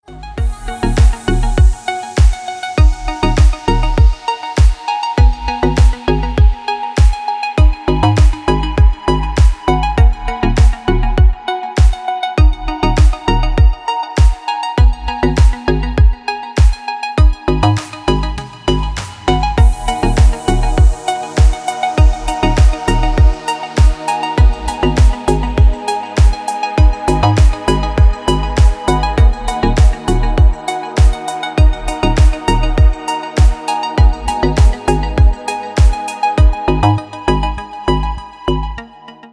Спокойные звонки, спокойные рингтоны